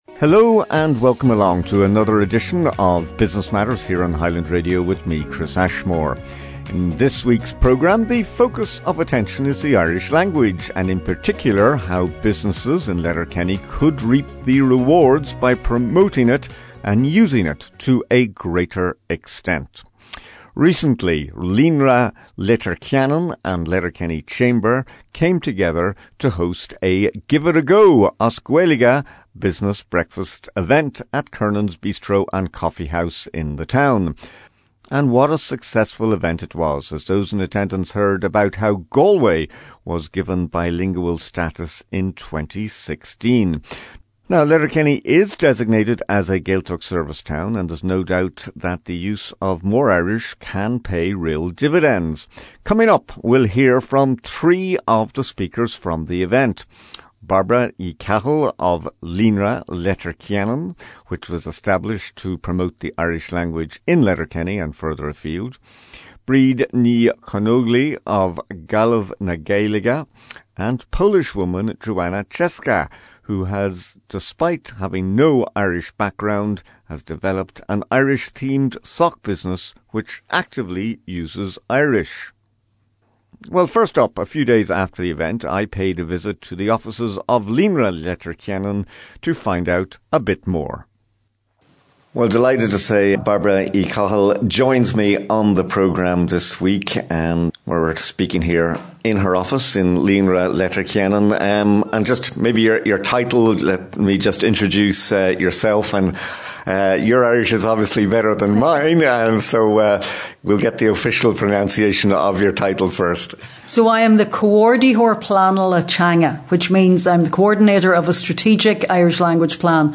Recently, Líonra Leitir Ceanainn and Letterkenny Chamber came together to host a Give it a Go – As Gaeilge business breakfast event at Kernans Bistro & Coffee House in the town.